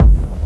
• Dark Steely Techno Kick Reverbed.wav
Dark_Steely_Techno_Kick_Reverbed_qmJ.wav